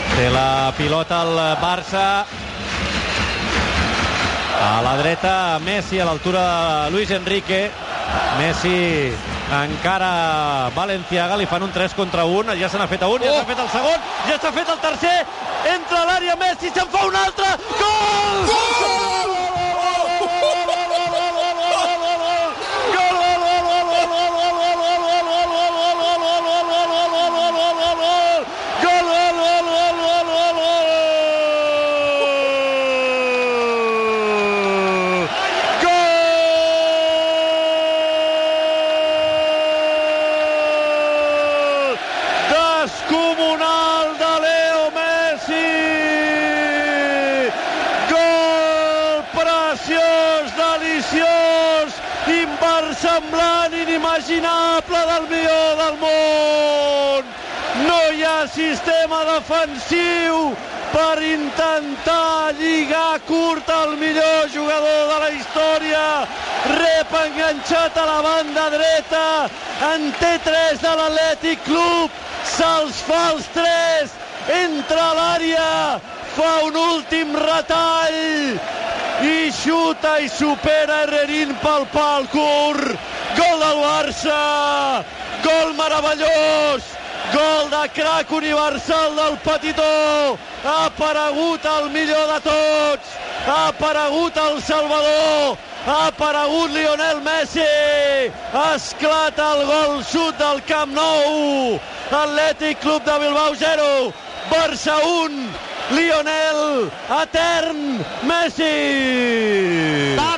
Transmissió del partit de la Copa del Rei de futbol masculí entre l'Athletic Club i el Futbol Club Barcelona.
Narració i lloa del gol de Leo Messi. Reconstrucció de la jugada.
Esportiu